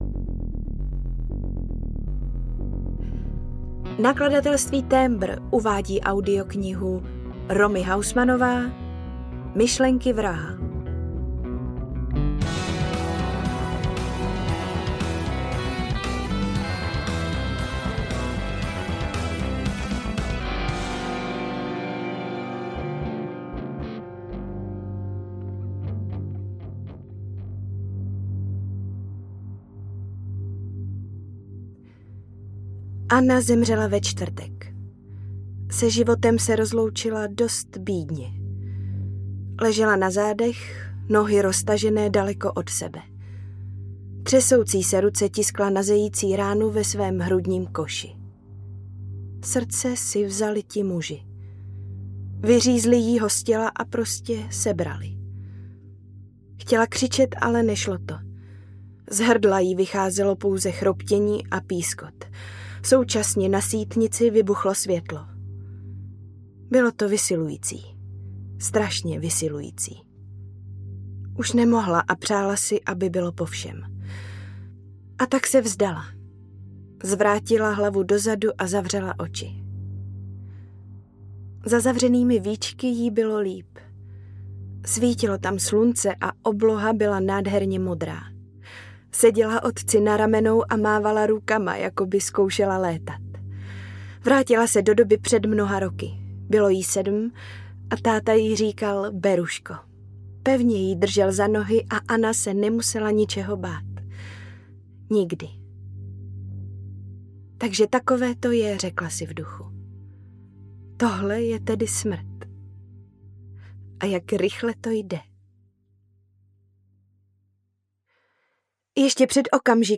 Myšlenky vraha audiokniha
Ukázka z knihy